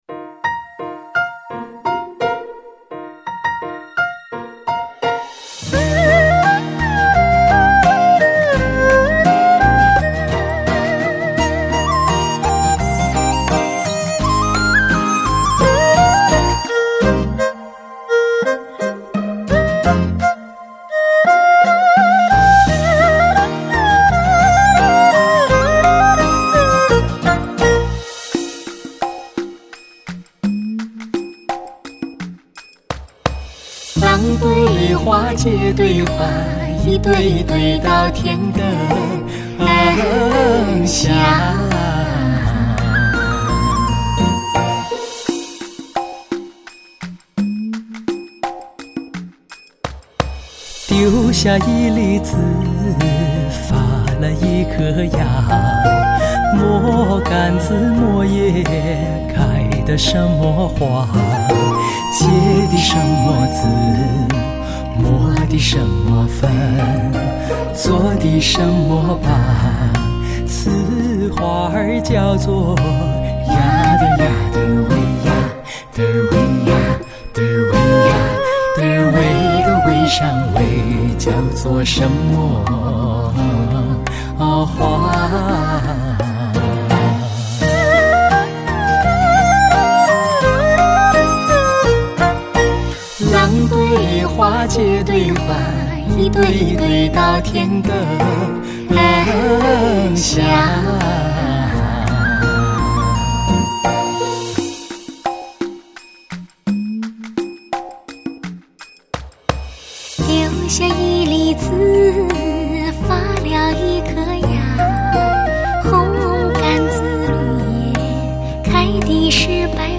男女经典对唱经典，岁月如歌，歌不尽一世繁华
淳朴流畅，明快抒情，雅俗共赏，怡情悦性，传承经典